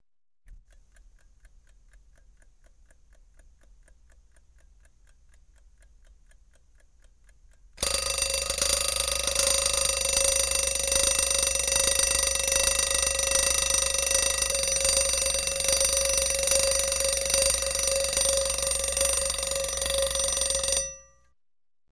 Звуки будильника
Будильник часов для видеороликов